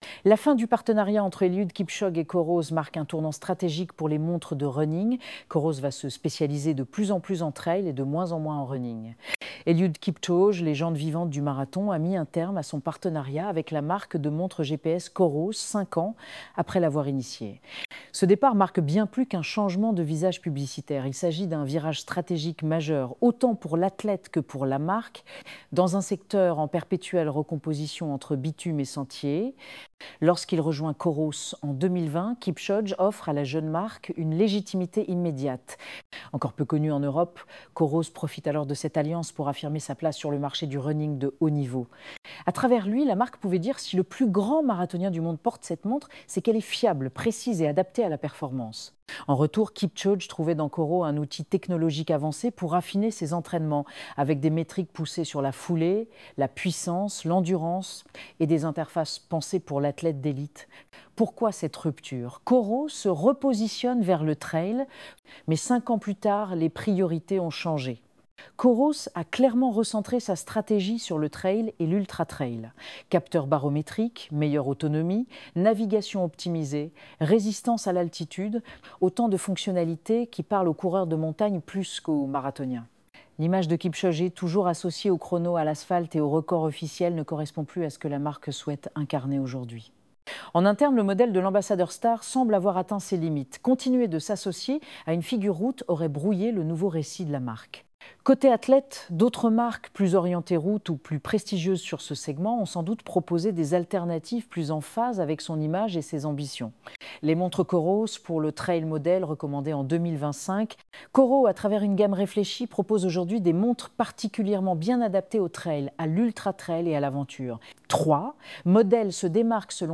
Sommaire Toggle ACHETER UNE MONTRE COROS Ecouter cet article sur la stratégie des montres de trail et de running Coros Eliud Kipchoge, légende vivante du marathon, a mis un terme à son partenariat avec la marque de montres GPS COROS, cinq ans après l’avoir initié.